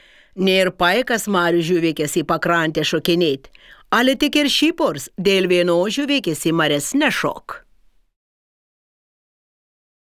Pasiklausyk šišioniškai